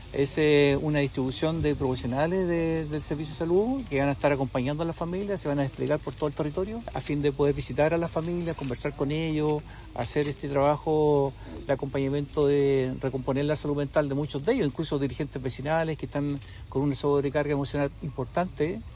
Asimismo, el alcalde de Tomé, Ítalo Cáceres, destacó la importancia de esta iniciativa, considerando el impacto emocional que dejó la emergencia en las personas.